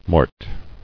[mort]